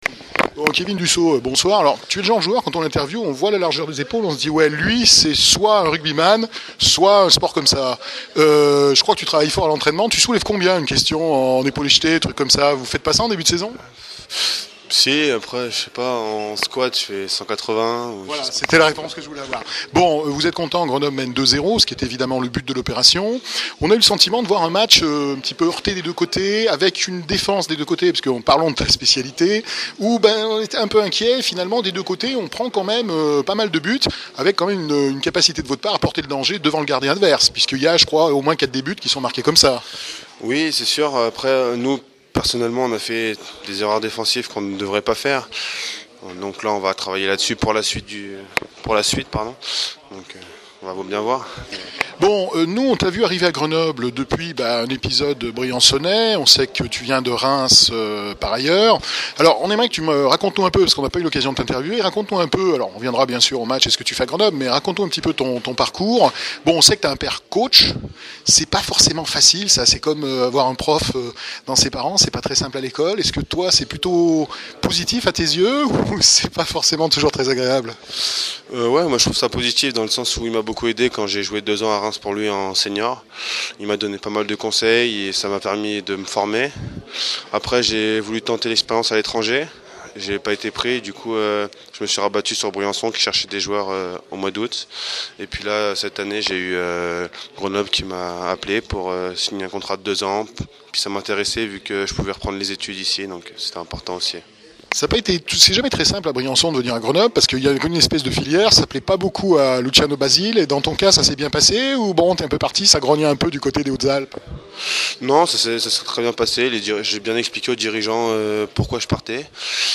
Hockey sur glace : Interview